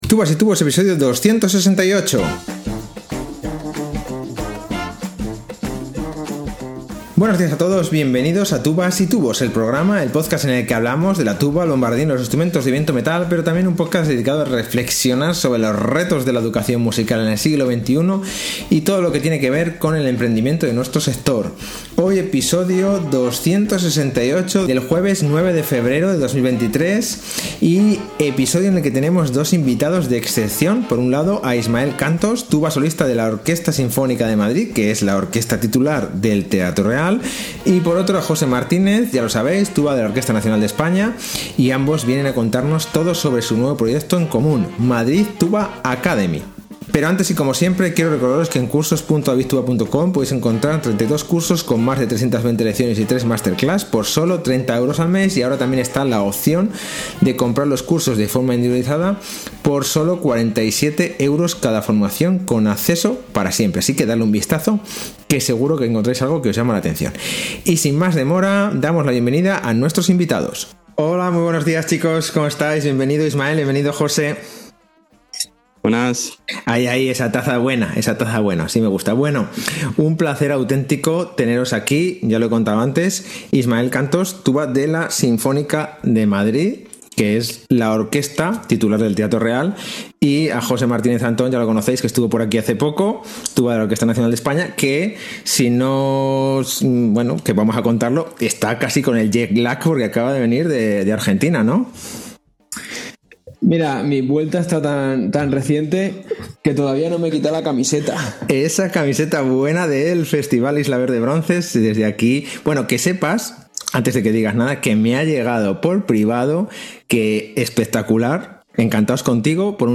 Hoy, en tubas y tubos, tenemos a dos invitados de excepción